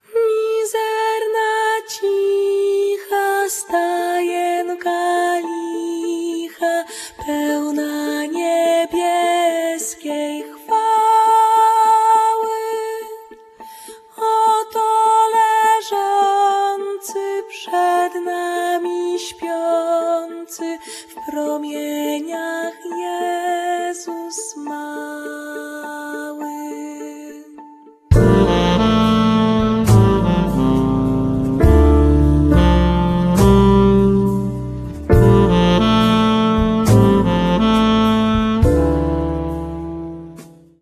instrumenty klawiszowe, kontrabas, sample, loopy
altówka
perkusja